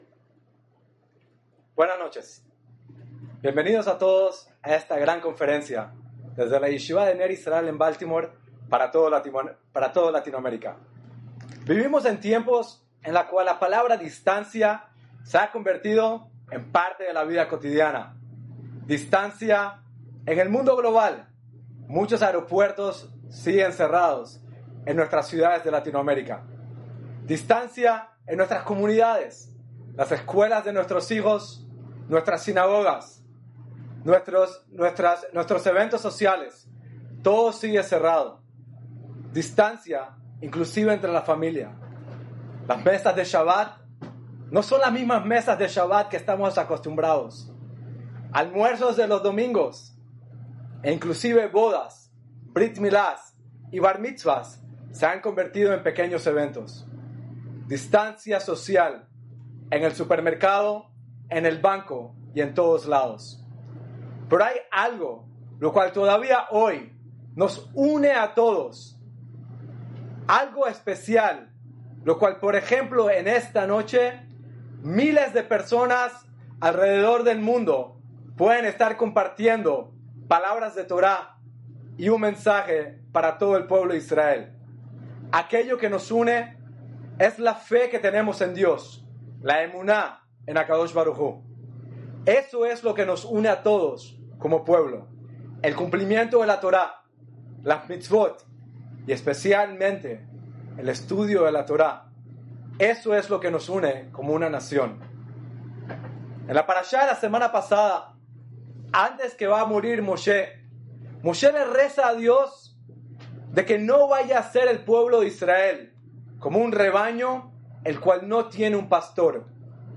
Special Lecture - Ner Israel Rabbinical College